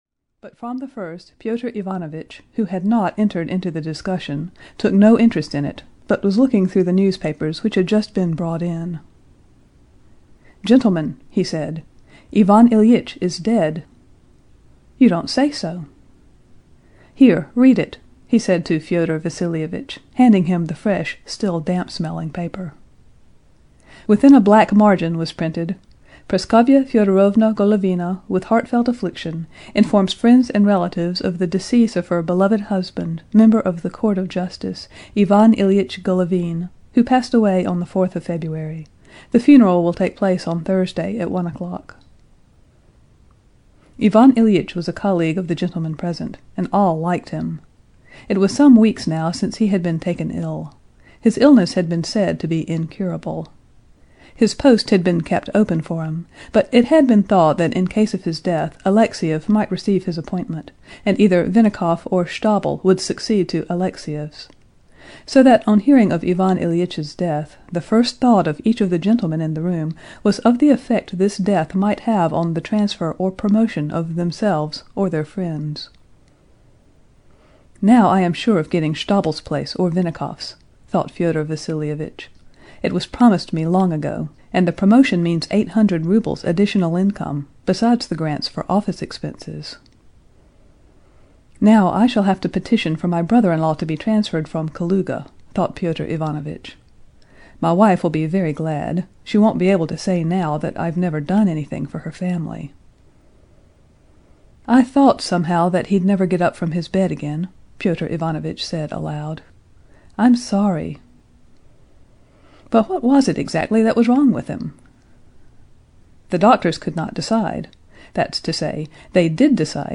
The Death of Ivan Ilyitch (EN) audiokniha
Ukázka z knihy